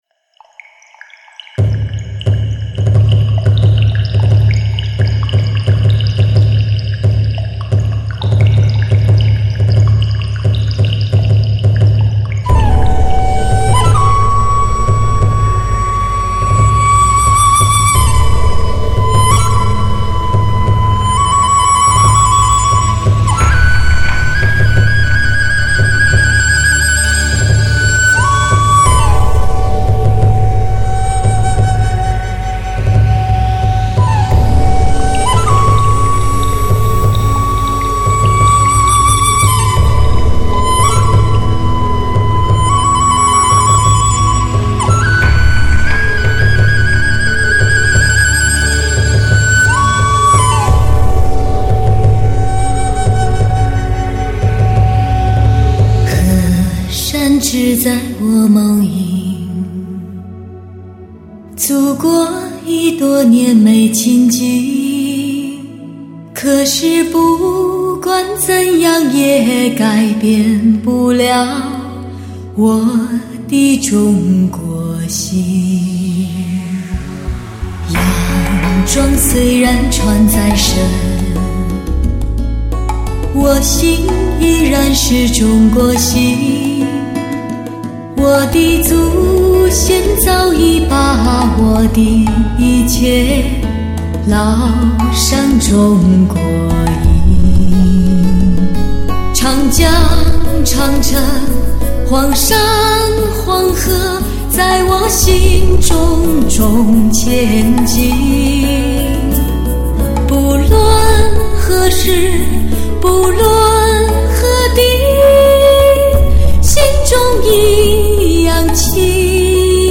绝色女声HI-FI极致
专辑格式：DTS-CD-5.1声道